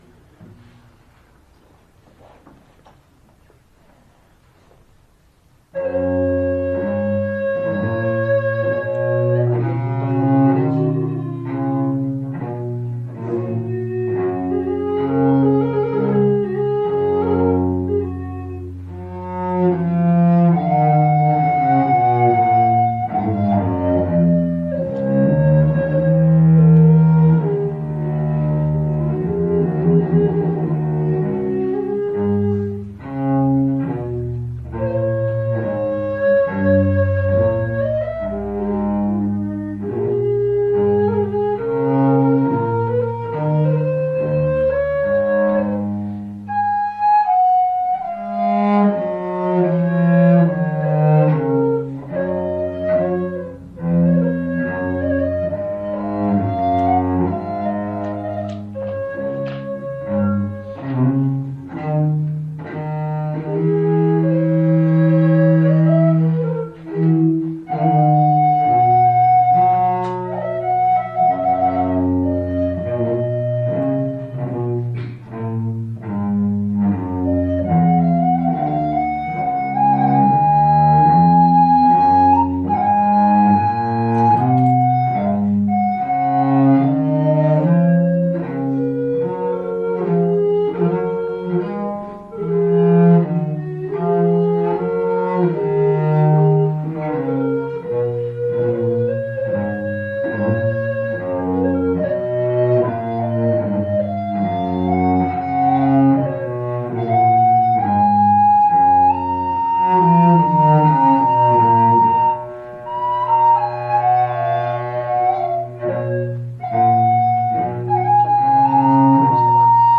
Concierto inicio Semana Musical